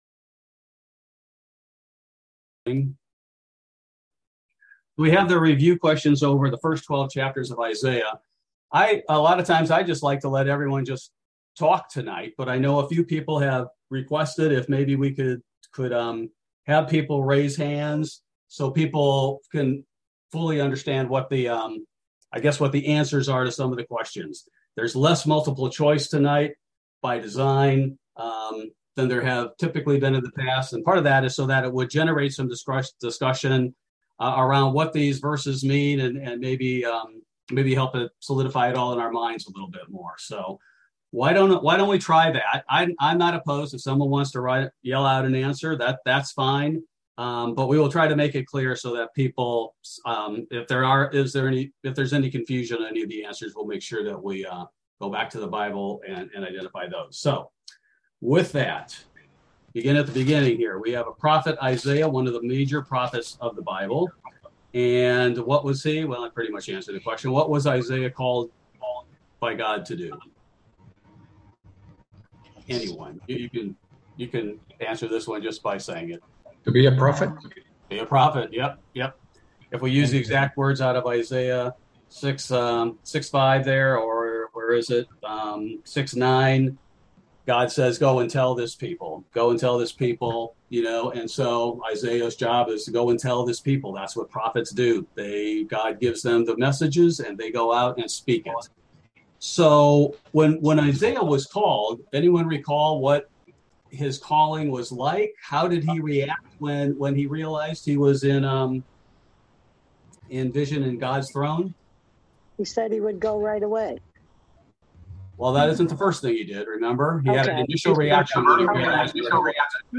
Bible Study: September 21, 2022